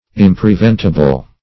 Impreventable \Im`pre*vent"a*ble\, a. Not preventable; inevitable.